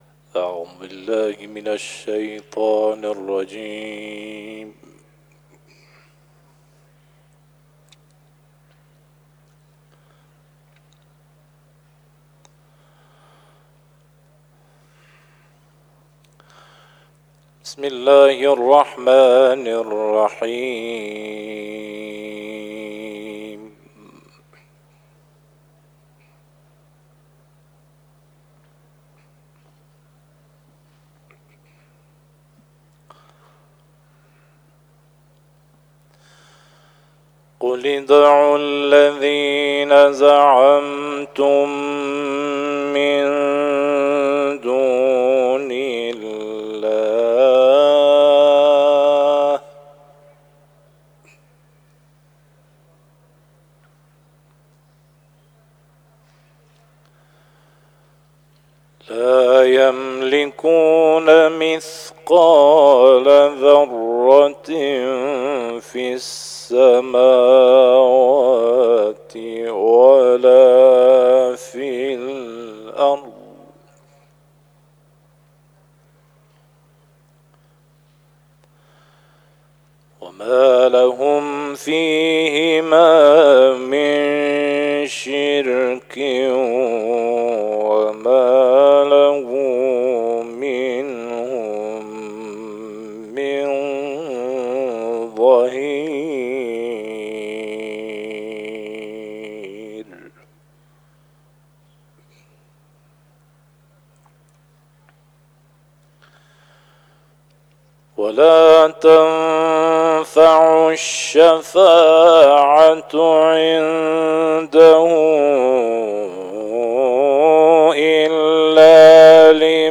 ، آیات 22 تا 27 سوره «سبأ» را در حرم حضرت ثامن‌الائمه(ع) تلاوت کرده است.
تلاوت